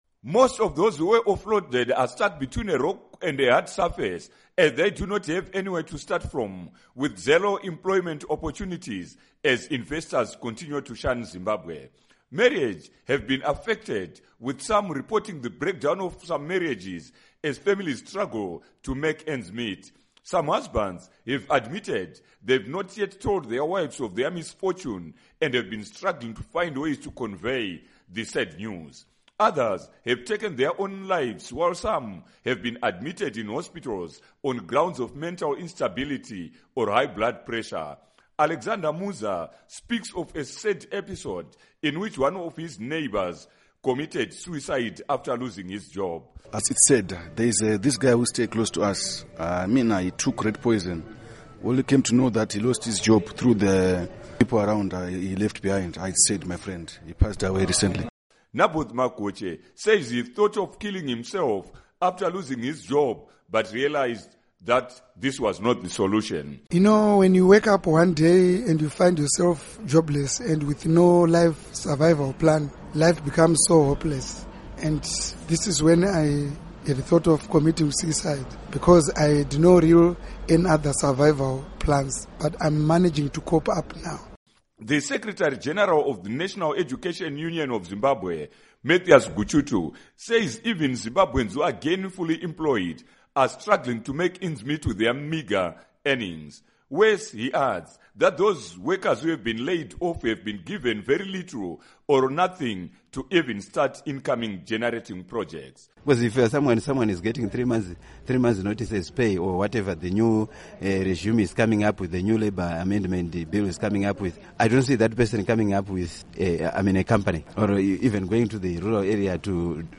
Report on Suffering Zimbabweans